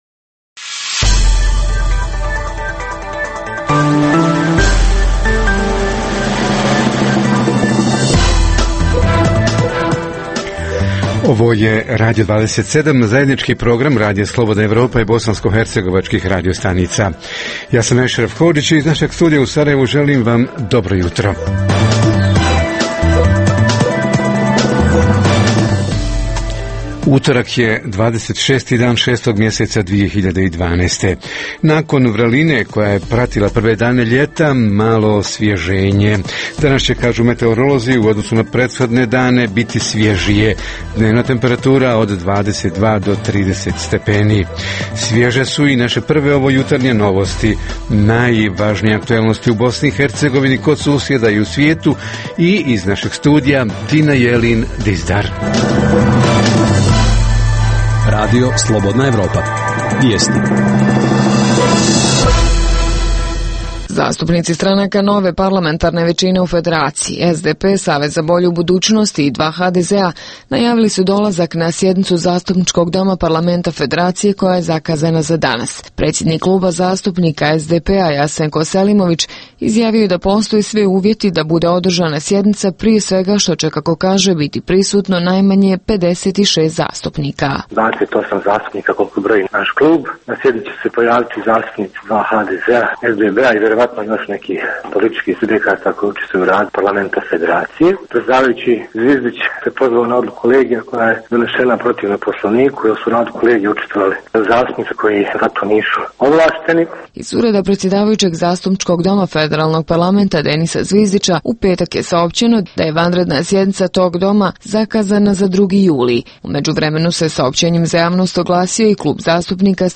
Jutarnji program za BiH emituje se uživo. Tema jutra: ekonomsko-socijalni uvjeti u kojima žive građani BiH – ko i kako protestira, koji su najčešći razlozi protestima i šta nedostaje za organizovaniji, širi i efikasniji socijalni bunt?
Redovni sadržaji jutarnjeg programa za BiH su i vijesti i muzika.